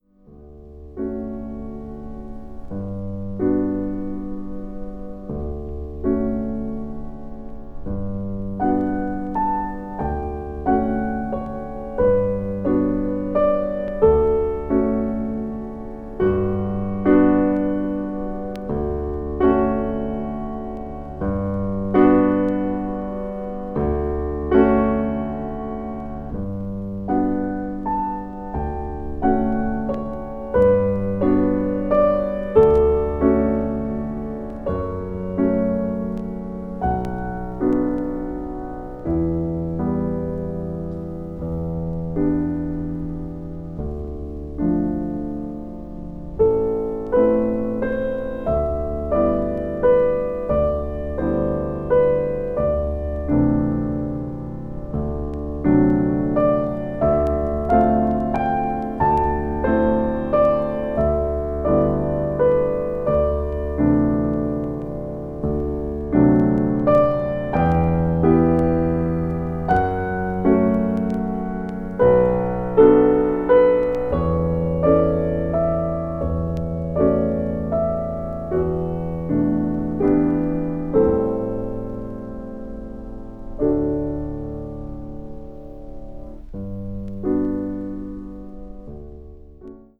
media : EX+/EX+(一部わずかにチリノイズが入る箇所あり)